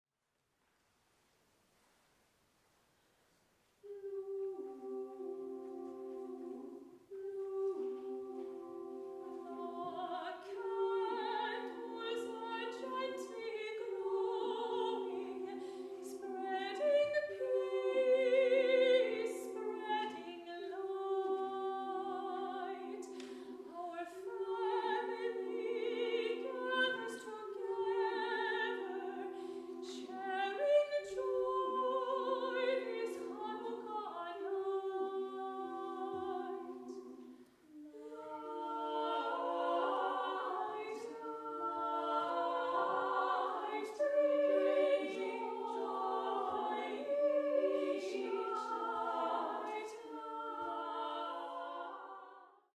Soprano
Bass
Mezzo-soprano
Tenor